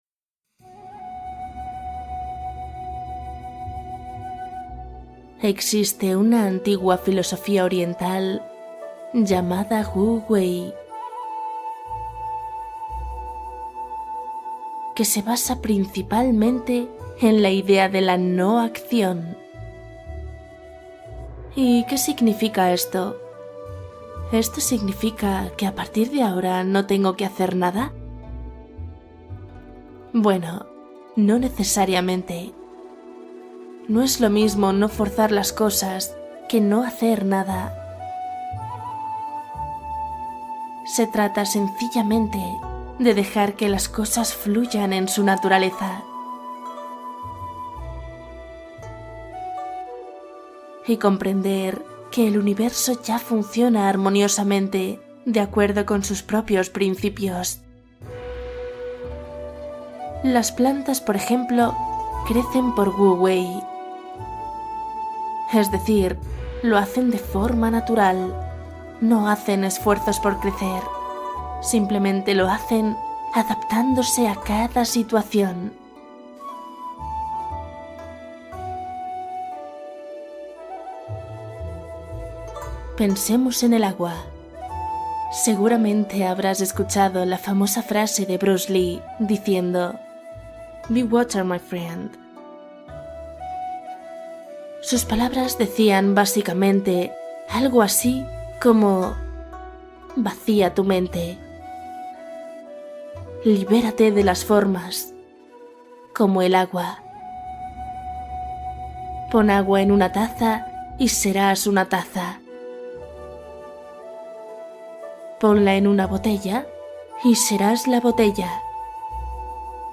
Wu Wei: relajación y meditación para un sueño sin esfuerzo